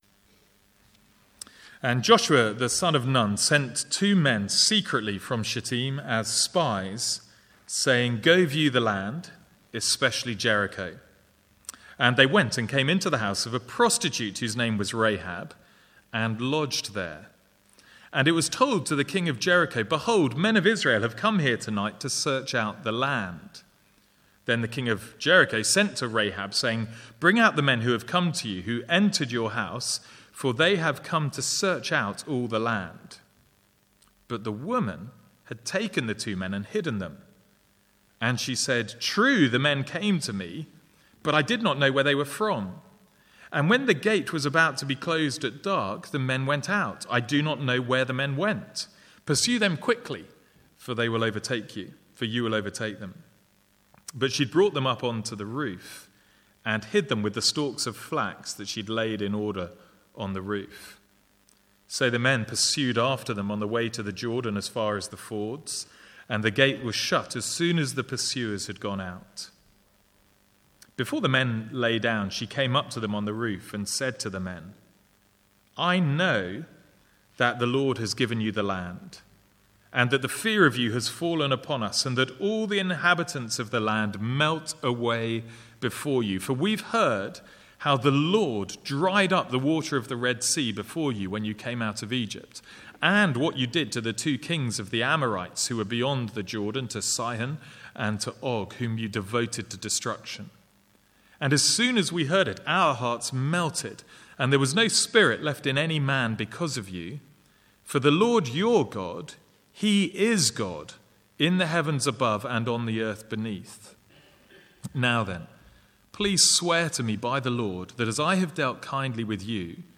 From the Sunday evening series in Joshua.